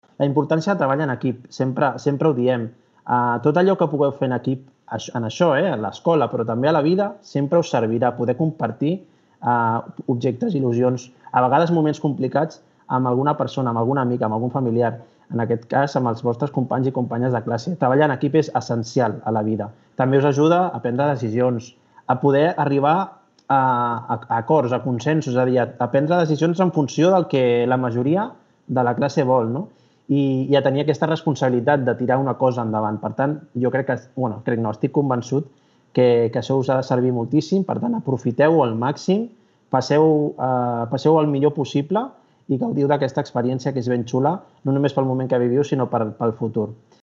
Carlos Cordón, alcalde de Cerdanyola: un aprenentatge per a la vida: